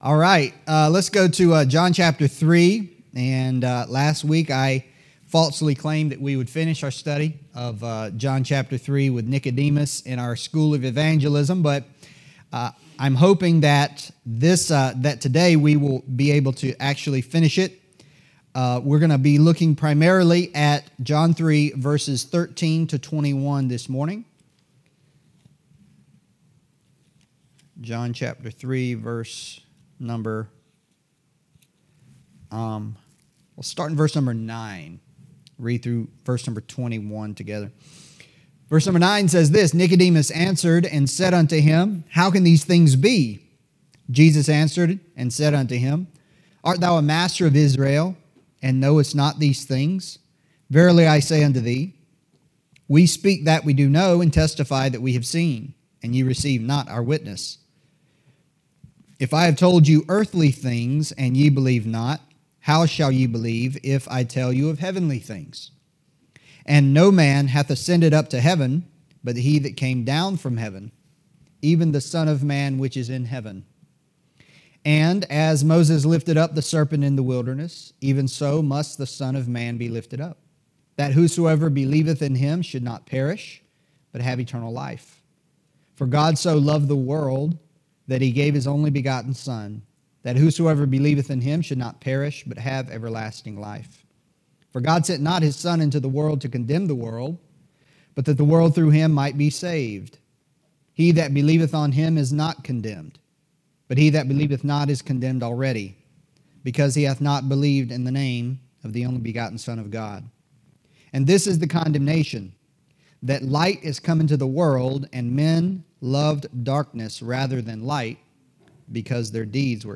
Adult Sunday School: School of Evangelism &middot